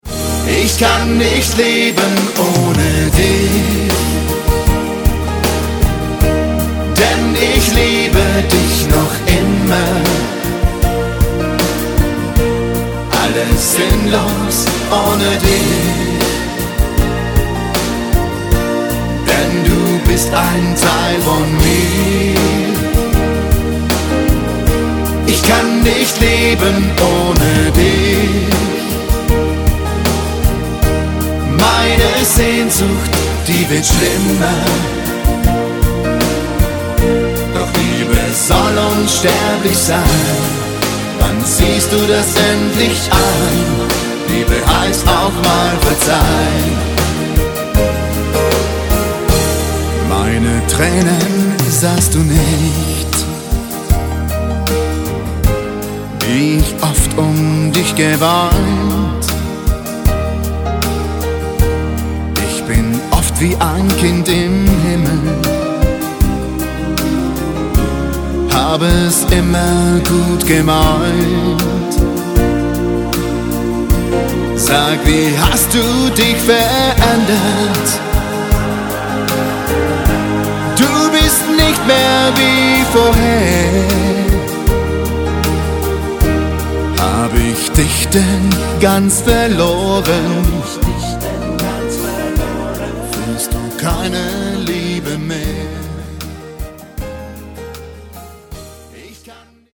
MODERN